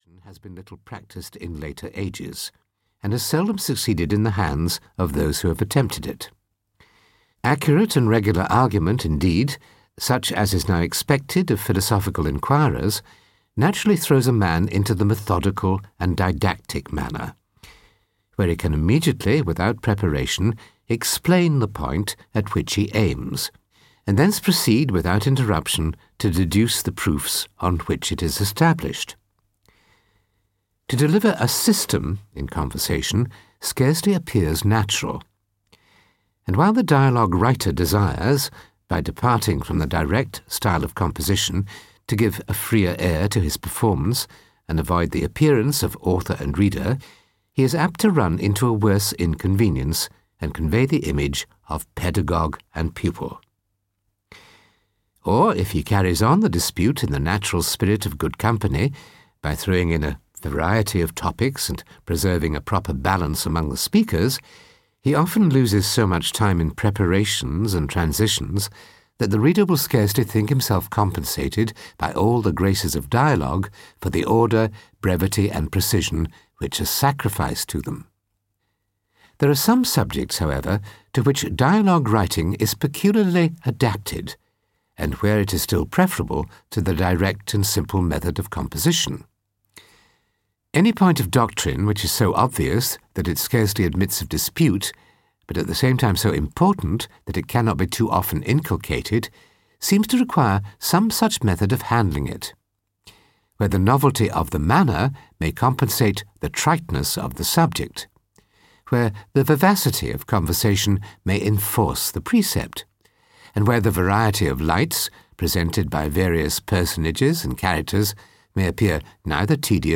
Audio knihaDialogues Concerning Natural Religion and The Natural History of Religion (EN)
Ukázka z knihy